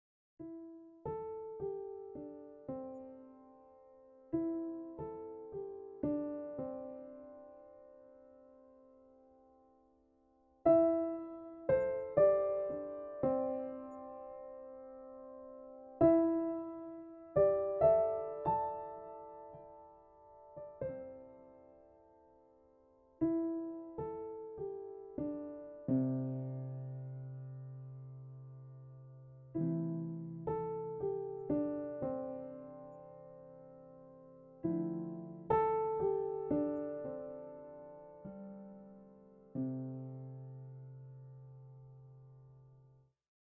描述：放松的氛围音乐
Tag: 音乐 WAV 氛围